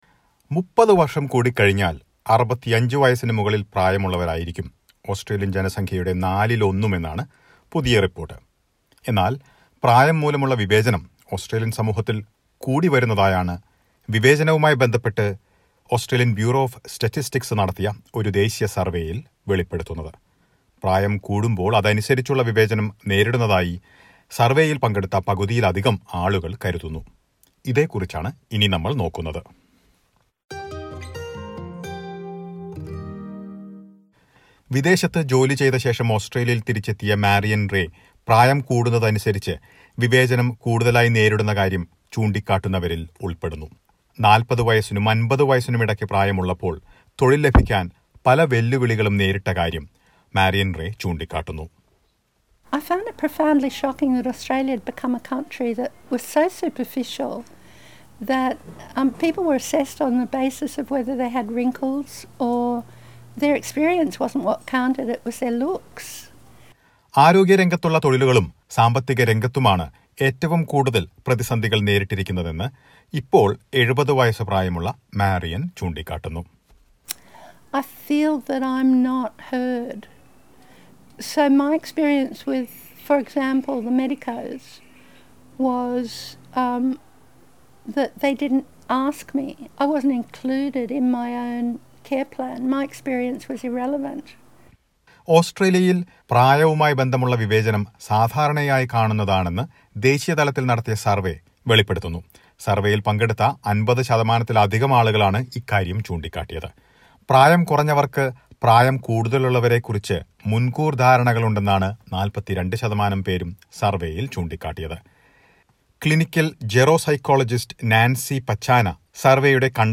Age-related discrimination is on the rise in Australia as per recent studies. Listen to a report.